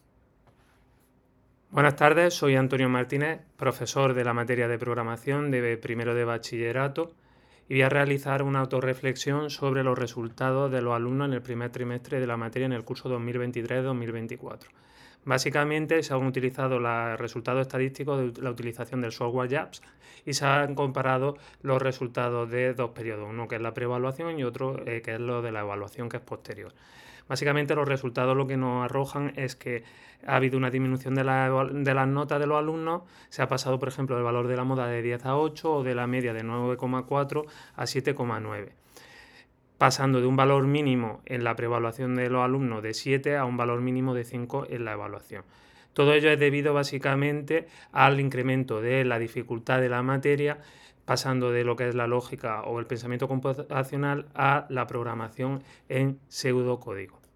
Evidencia 3 (E3). Registro de la autorreflexión del profesor de la materia sobre los resultados de las estadísticas obtenidas en JASP.
Autoreflexión-resultados-1ºT.m4a